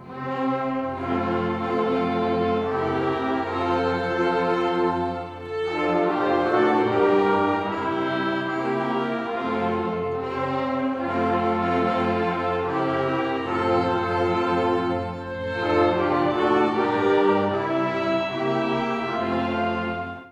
Kostproben vom Weihnachtskonzert 2024:
Oh Tannenbaum – Juniororchester Streicher und Bläser
Weihnachtskonzert2024_Oh_Tannenbaum_Juniororchester_Streicher_und_Blaeser.wav